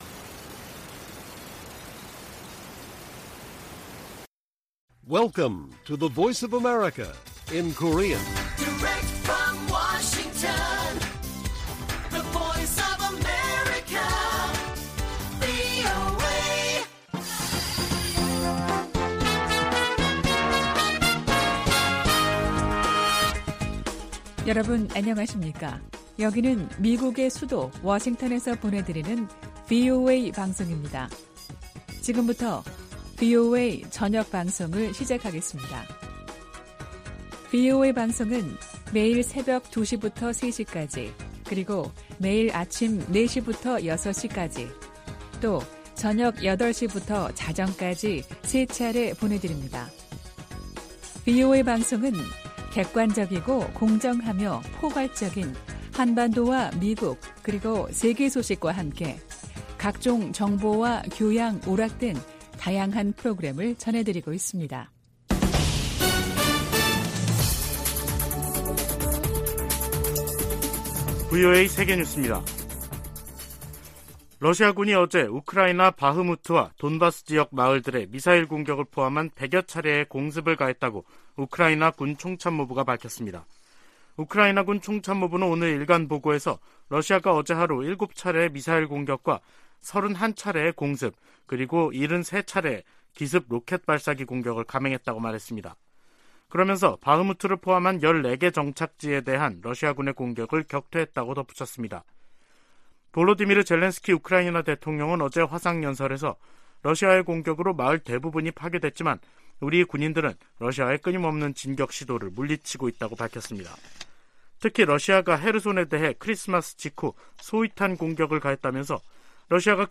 VOA 한국어 간판 뉴스 프로그램 '뉴스 투데이', 2023년 1월 9일 1부 방송입니다. 미 국무부는 6년째 공석인 북한인권특사 임명 여부와 관계 없이 미국은 북한 인권 문제에 집중하고 있다고 밝혔습니다. 미국의 한반도 전문가들은 실효성 논란에 휩싸인 9.19 남북 군사합의와 관련해, 이를 폐기하기보다 북한의 도발에 대응한 ‘비례적 운용’이 더 효과적이라고 제안했습니다.